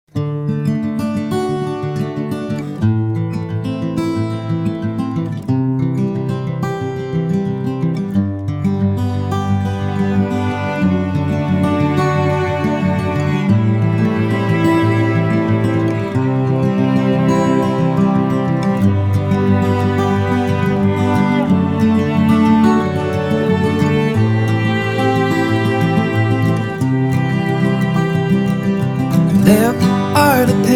Caption: This is a calm and serene Acoustic Folk instrumental, designed to evoke a peaceful, pastoral atmosphere.
The guitar plays a flowing pattern of arpeggiated major chords, creating a warm and resonant harmonic bed. After a few bars, a lush string quartet (two violins, viola, cello) enters with a soft, legato counter-melody. These strings provide long, soaring, and emotionally rich phrases that swell and fall gently, adding a layer of sophisticated elegance. Supporting this, a second acoustic guitar is strummed very softly with the fleshy part of the thumb, adding a gentle rhythmic pulse and a soft percussive texture that sits quietly in the background. The cello plays a simple, warm bassline that anchors the harmony, while the violins and viola carry the main string melody. The entire mix is airy and spacious, recorded with a touch of natural room reverb to give it a dreamy, open-field feeling. The production is clean and warm, emphasizing the organic texture of the acoustic instruments.
BPM: 90.0